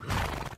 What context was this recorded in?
Fix stereo sounds; merge into mono